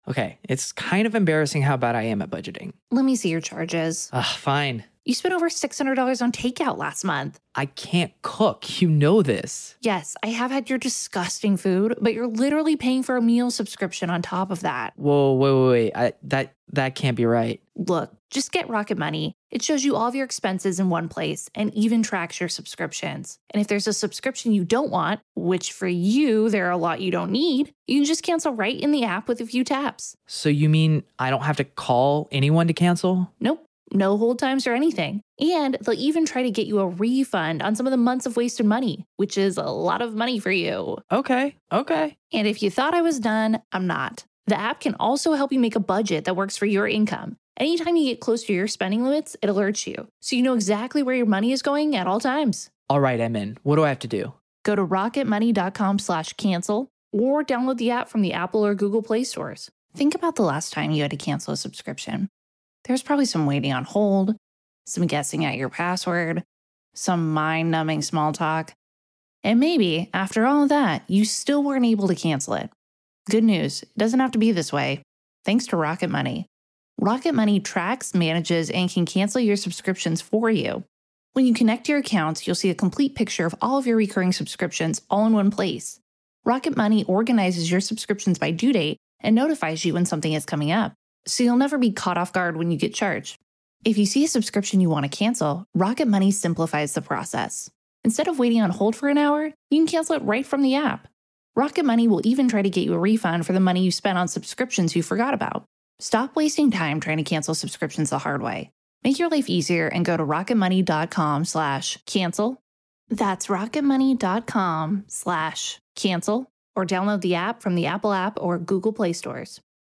The latest Spanish News Headlines in English: April 6th am